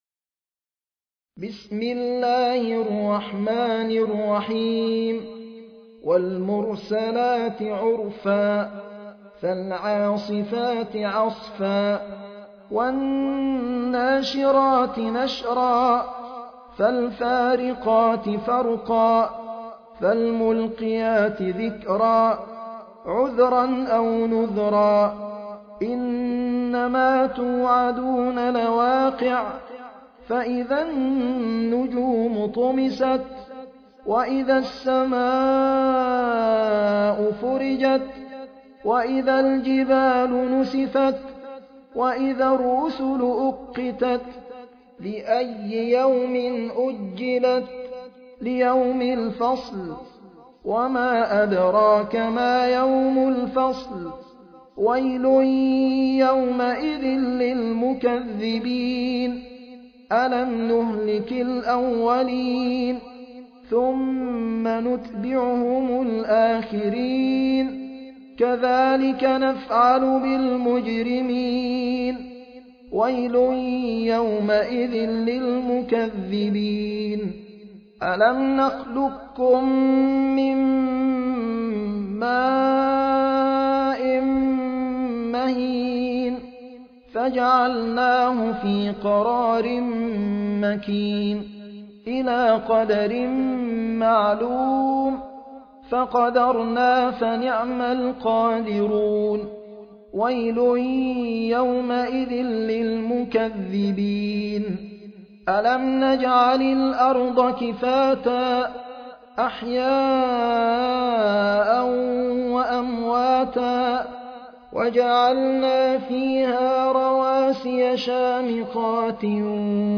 المصحف المرتل - حفص عن عاصم - المرسلات